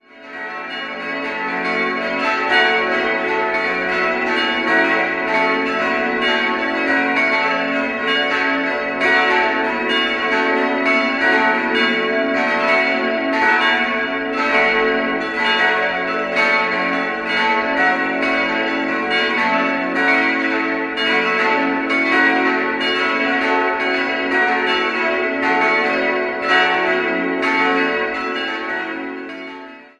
Das heutige Erscheinungsbild geht auf die letzten großen Renovierungsmaßnahmen von 2004 bis 2006 zurück. 5-stimmiges Geläut: fis'-a'-h'-cis''-e'' Vier Glocken wurden von Friedrich Wilhelm Schilling im Jahr 1960 gegossen, die zweitgrößte stammt aus dem Jahr 1688.